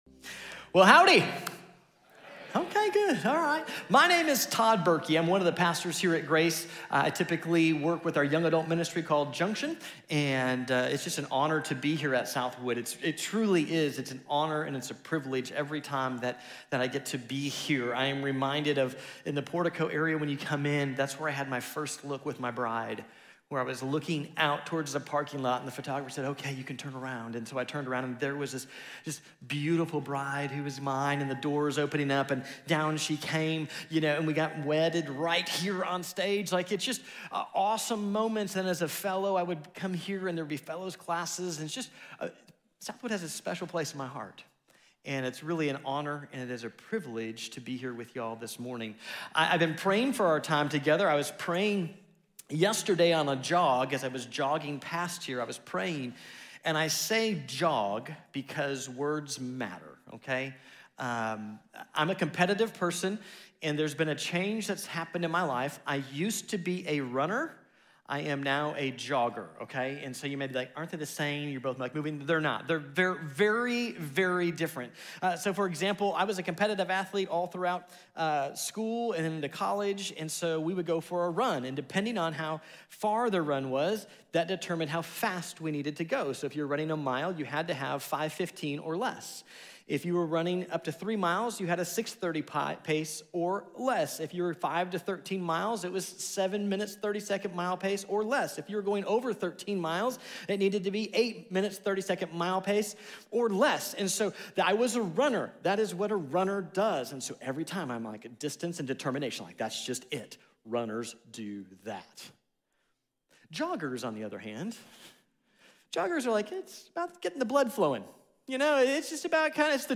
A Heart Of Justice | Sermon | Grace Bible Church